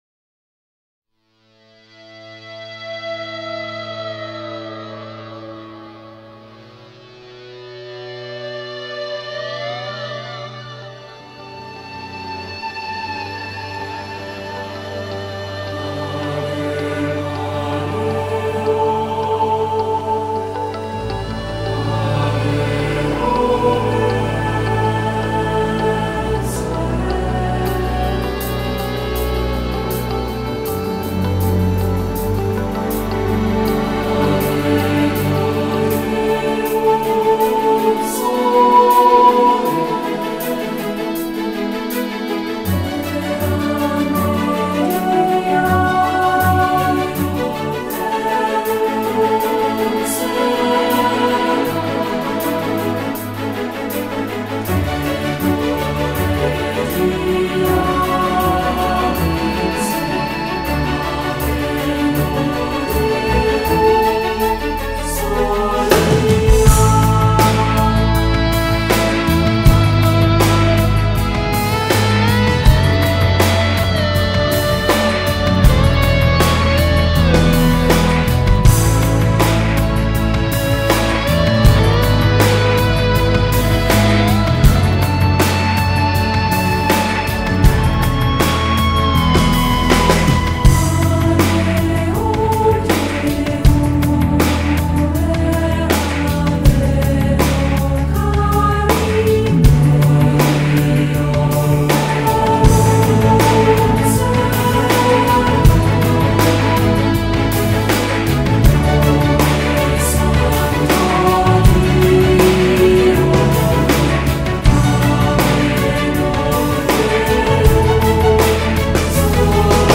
New Age, Gregorian Chant, Electronic, World Music